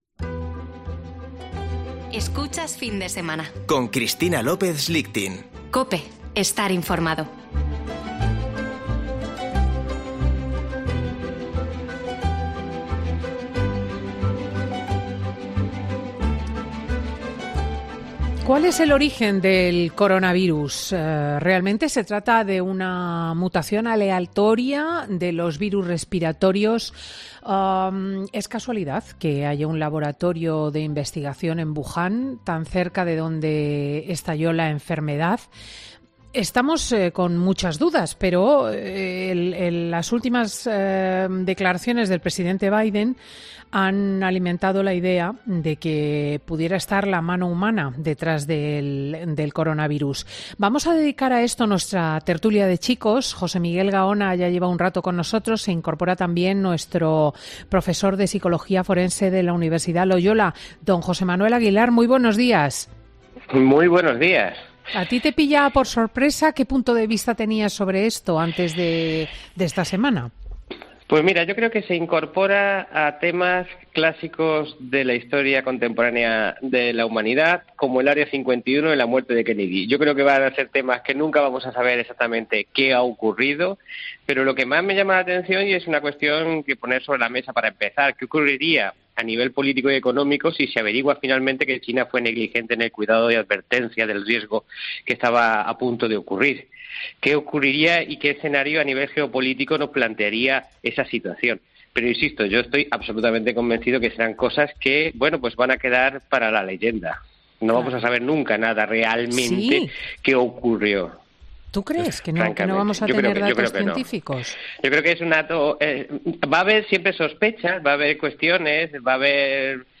Tertulia de Chicos: el origen del coronavirus